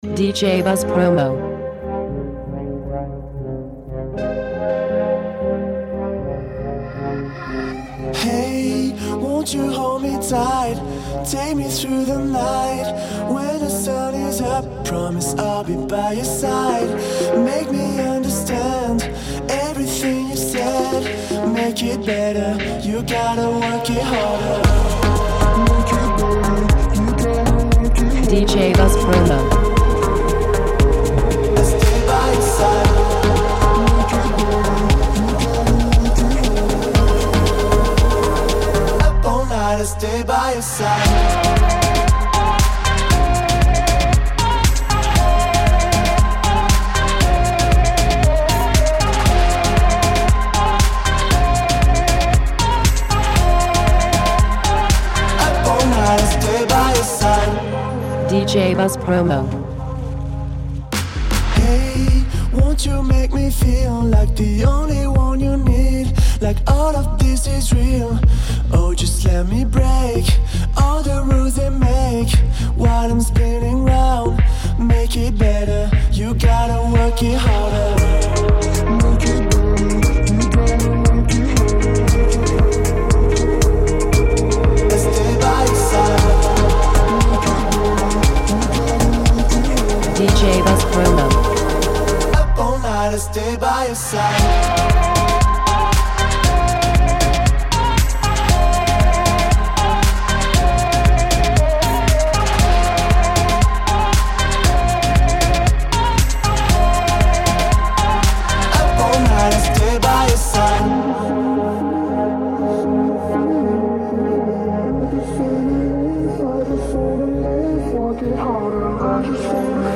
Girly!